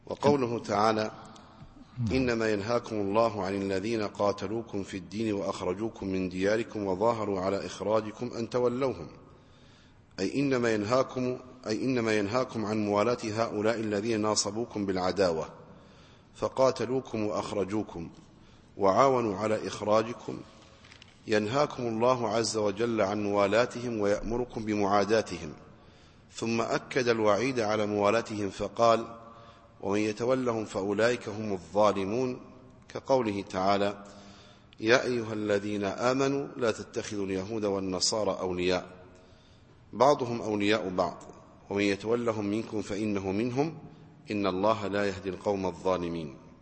التفسير الصوتي [الممتحنة / 9]